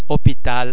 The sound's written representations are: o ô au
o_hopital.mp3